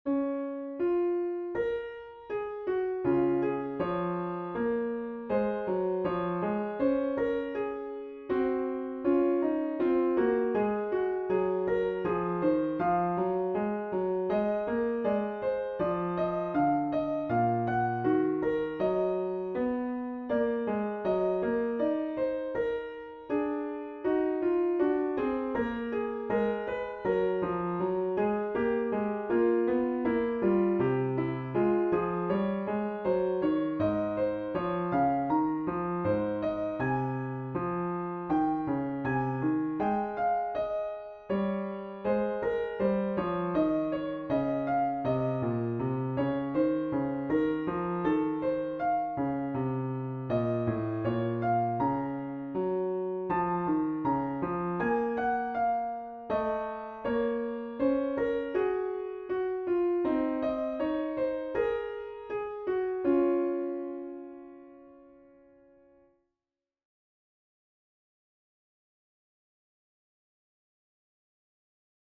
Invention in Db for Piano